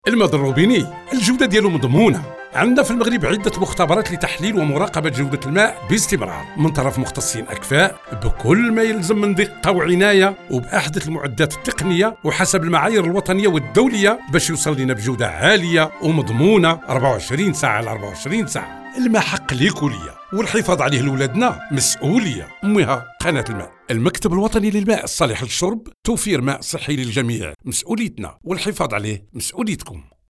Spots radio:
onep arabe  laboratoire 30 sec.mp3